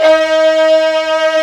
STR VIOLI0IL.wav